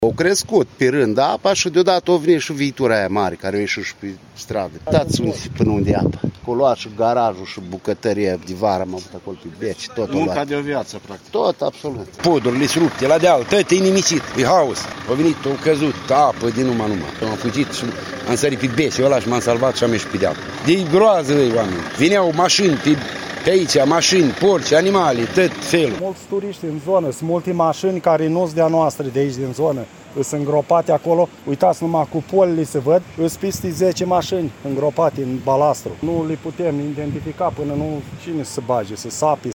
Corespondență de la fața locului